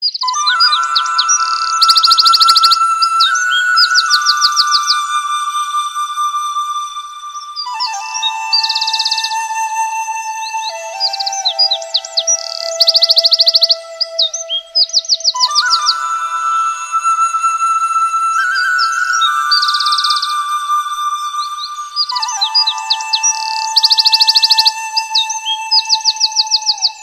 Categoría Clasicos